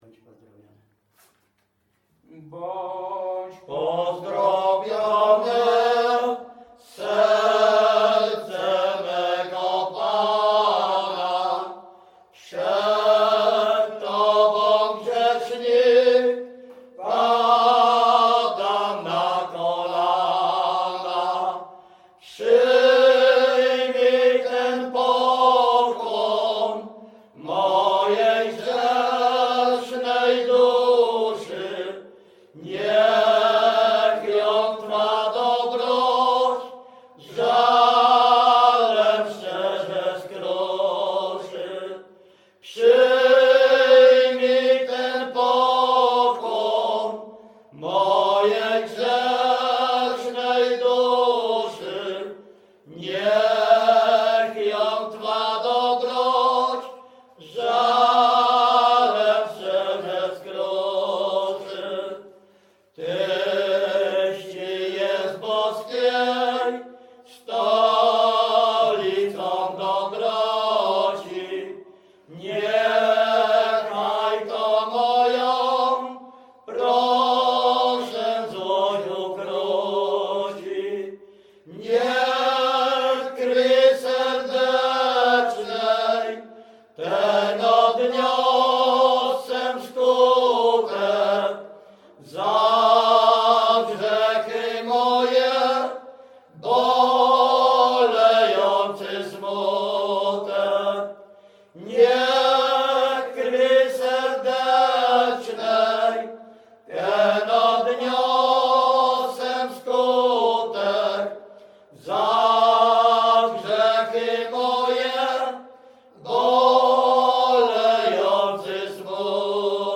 Pogrzebowa
Array nabożne katolickie pogrzebowe